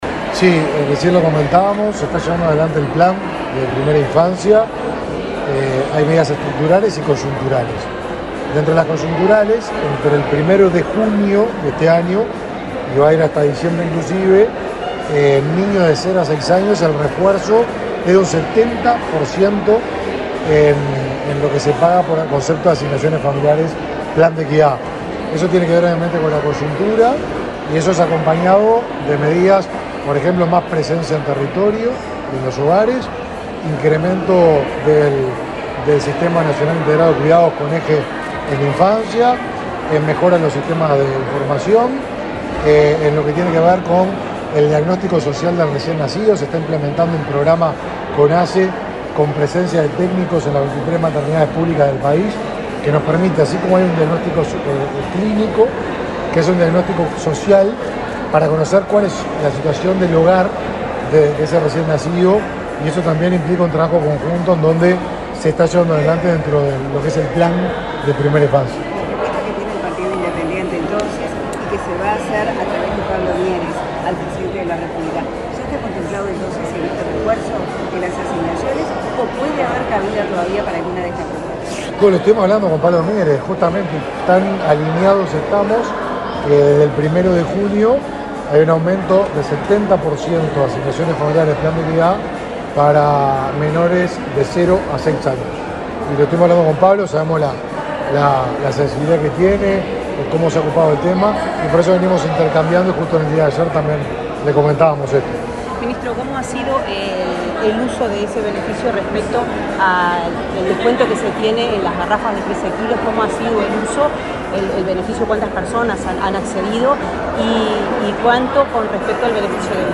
Declaraciones del ministro de Desarrollo Social, Martín Lema
Declaraciones del ministro de Desarrollo Social, Martín Lema 16/11/2022 Compartir Facebook X Copiar enlace WhatsApp LinkedIn El presidente del INAU y el ministro de Desarrollo Social, Martín Lema, participaron en la conferencia por el 33.° aniversario de aprobada la Convención de los Derechos del Niño en la Asamblea General de Naciones Unidas. Luego Lema dialogó con la prensa.